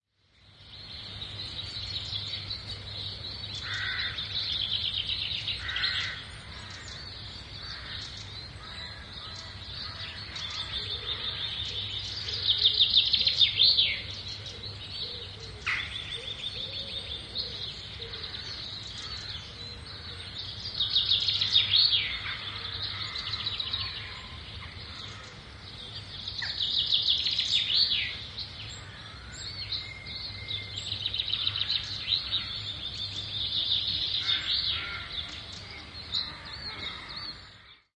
描述：清晨，苏格兰西南部Eskdalemuir的Samye Ling藏传佛教中心的鸟鸣。录音。奥林巴斯LS3 + OKM双耳话筒
Tag: 鸟类 桑耶寺 Lings 农村 声音 乌鸦 鸟的歌声 黎明合唱 西南 苏格兰